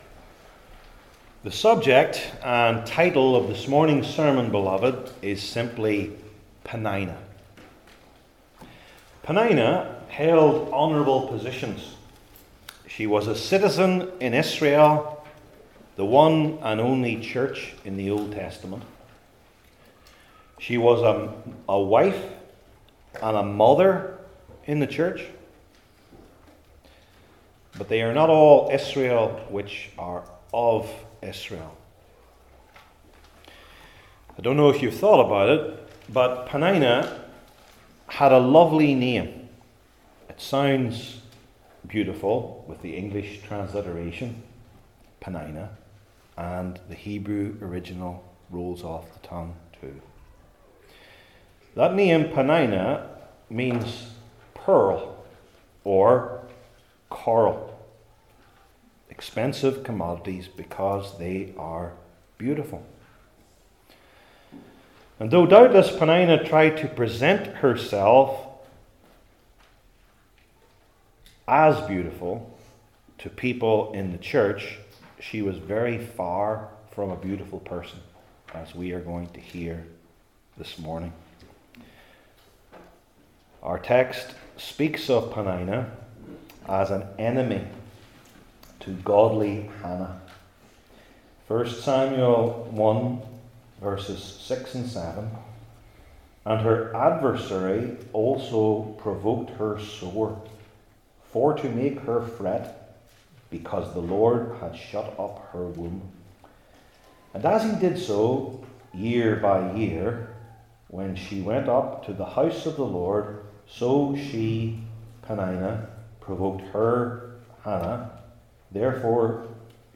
Old Testament Individual Sermons I. The Sins II.